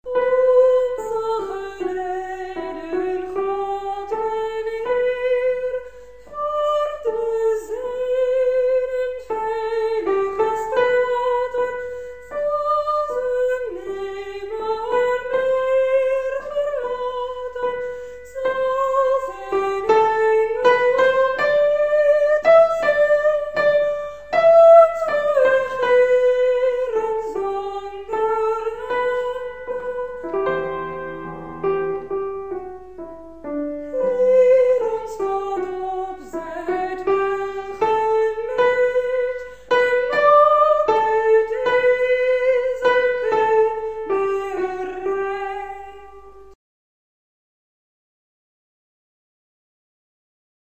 ingezongen in huiselijke kring